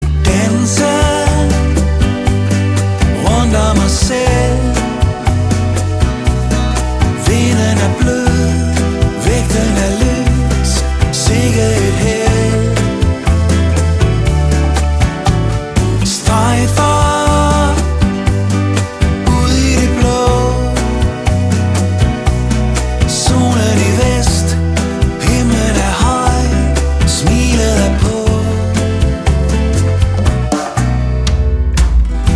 • Pop
• Rock
• Singer/songwriter